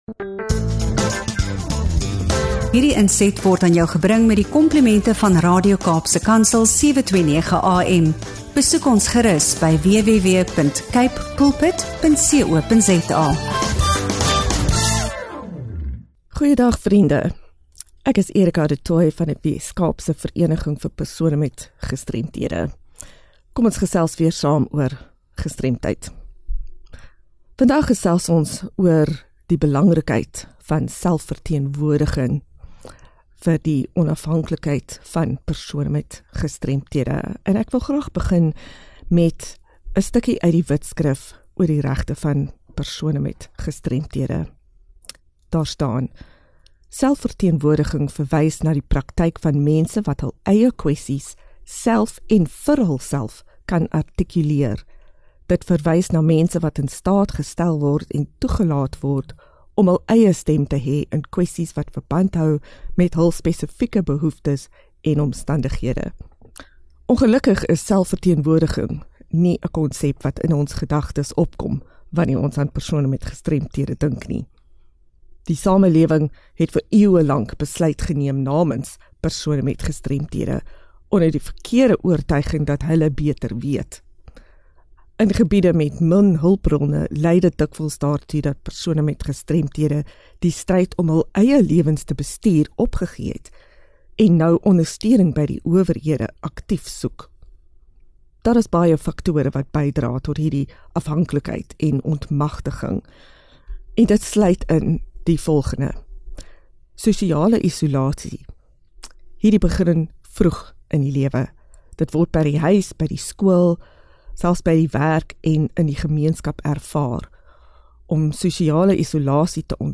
Hierdie gesprek is noodsaaklik vir enigiemand wat belangstel in menseregte, inklusiwiteit, gestremdheidsregte en die ontwikkeling van sterker, selfvoorspraaksame gemeenskappe.